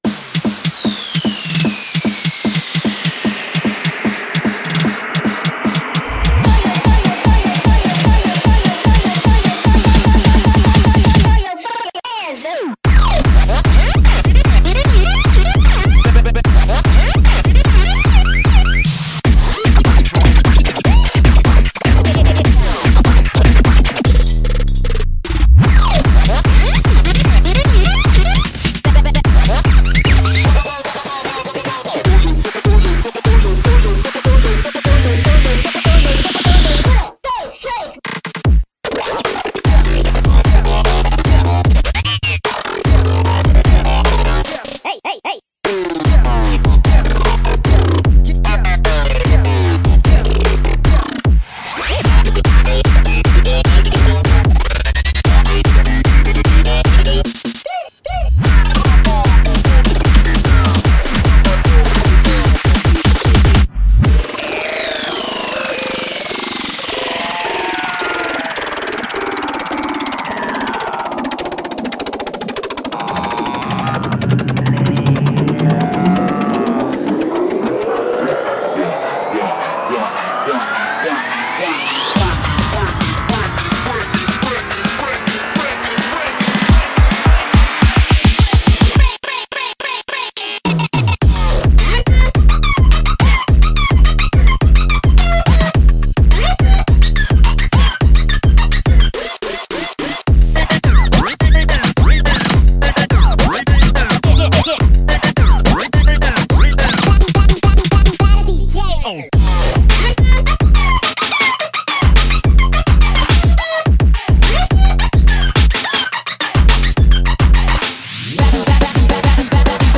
BPM53-300
Audio QualityMusic Cut
NOTE: The 300 BPM parts are brief.